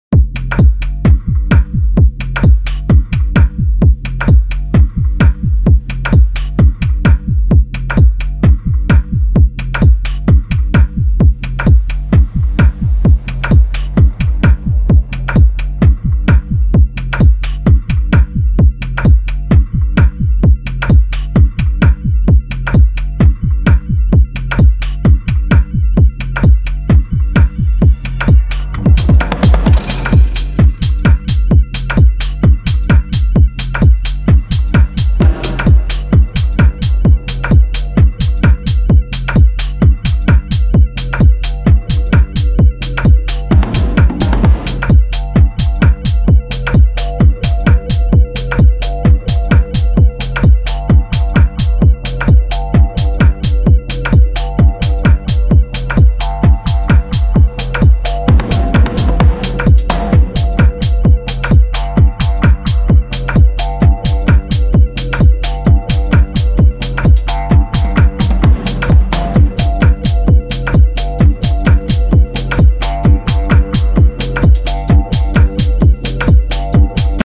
ヒプノティックなシンセリフや差し込まれるエフェクティヴなパーカッションで深い時間のフロアを揺さぶる